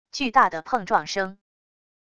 巨大的碰撞声wav音频